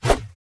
swing3.wav